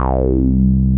RUBBER C3 F.wav